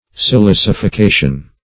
silicification - definition of silicification - synonyms, pronunciation, spelling from Free Dictionary
Search Result for " silicification" : The Collaborative International Dictionary of English v.0.48: Silicification \Si*lic`i*fi*ca"tion\, n. [See Silicify .]